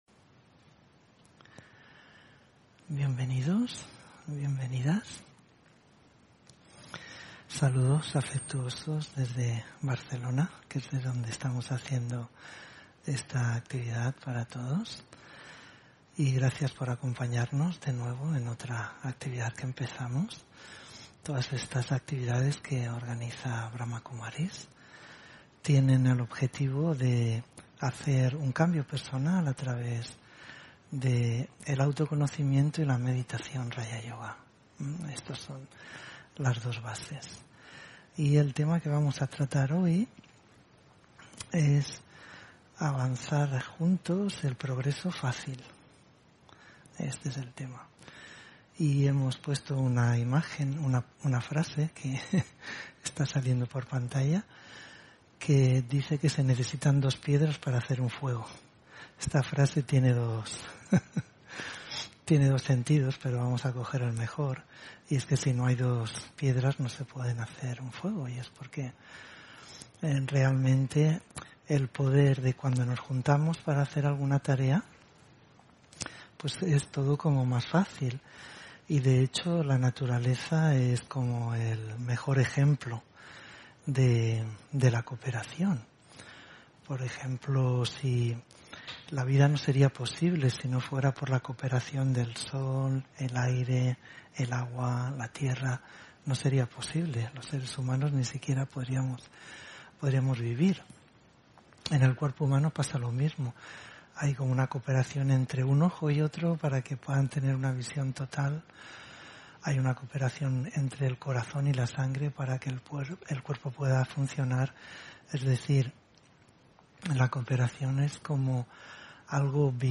Meditación y conferencia: Avanzar juntos, el progreso fácil (25 Noviembre 2021)